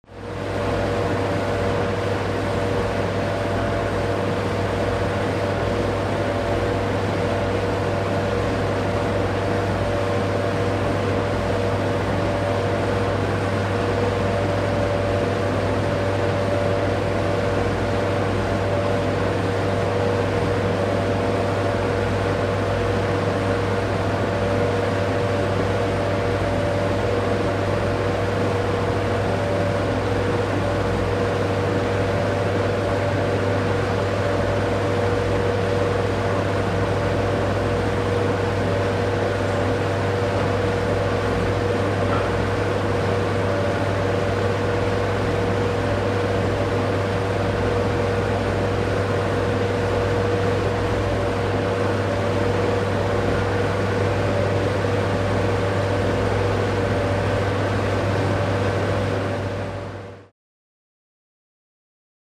Hum, Machine | Sneak On The Lot
Interior Freezer With Machine Hum.